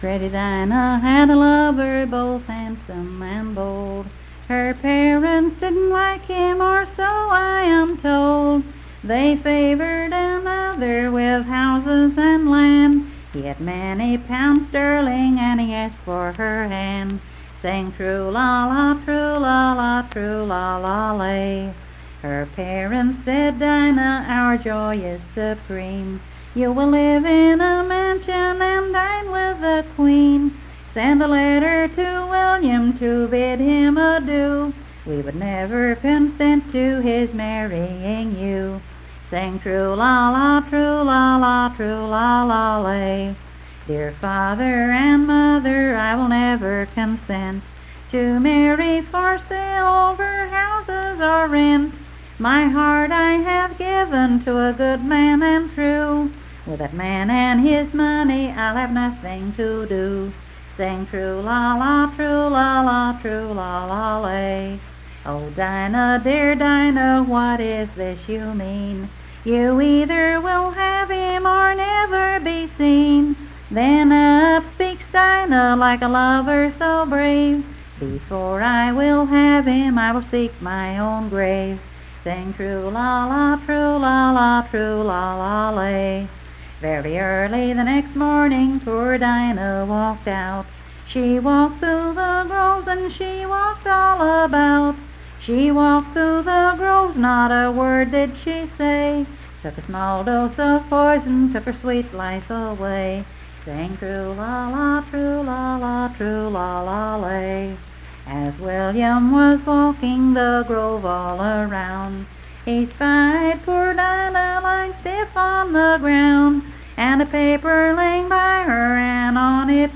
Unaccompanied vocal music
Performed in Coalfax, Marion County, WV.
Voice (sung)